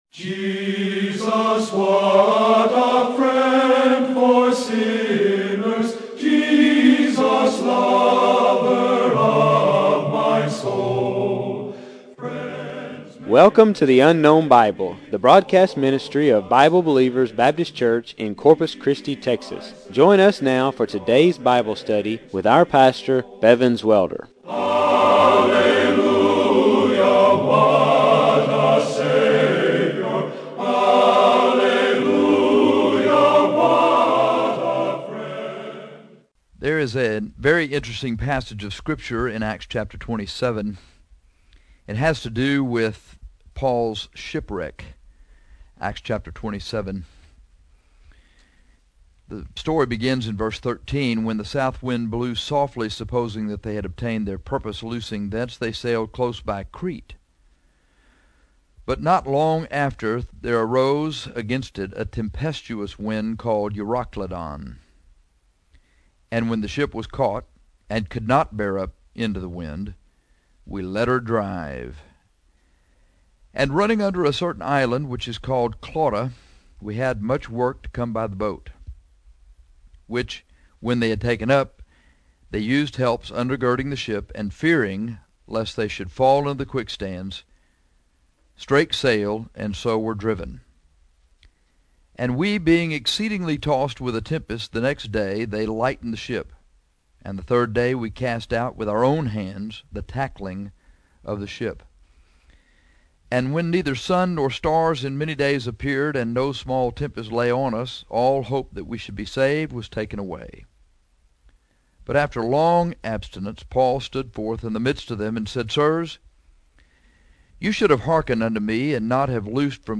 I preached this message to help you to cheer up my brother. You are overworked, hopeless, tired, scared and undernourished.